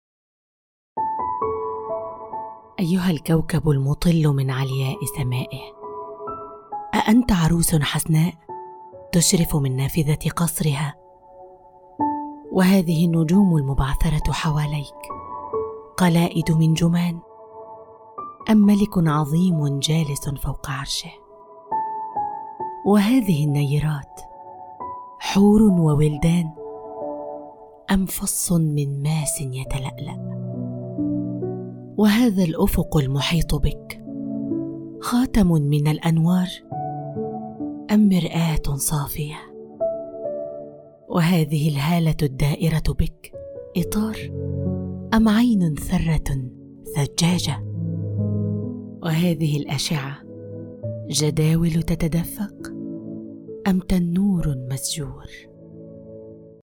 Micrófono: Rode NT1-A
Estudio: Estudio casero con tratamiento profesional para una acústica óptima